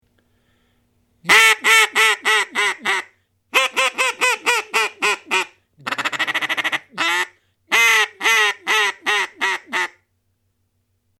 Reclamo de pato: Haydel's VR-00.
Diseñado para lograr una excelente imitación del sonido de las hembras de los patos salvajes.
Reclamo capaz de emitir dos sonidos diferentes, simulando dos ánades reales, el sonido que emite es realmente espectacular, no habrá pato que se resista a tus llamadas.